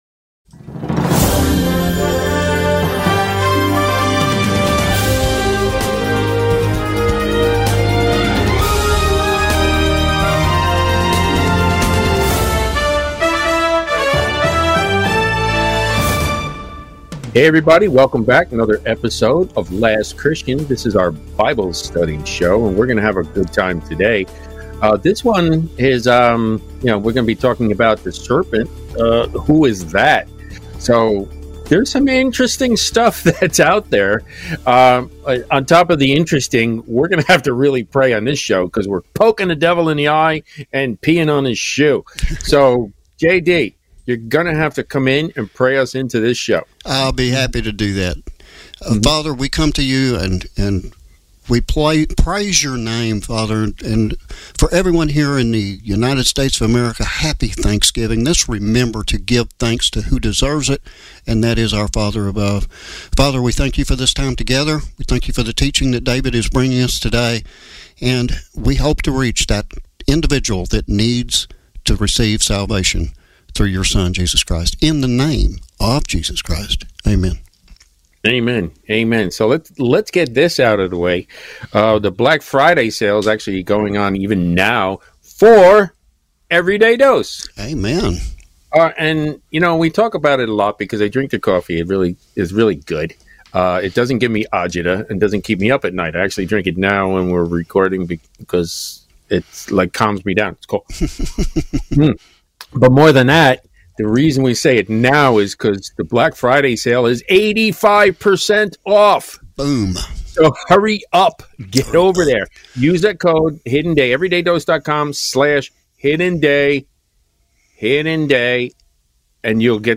The "Last Christian" is Presented every Tuesday, Thursday and Saturday evening at 7:30pm Central across ALL Platforms with Scripture taken directly from the Word of God.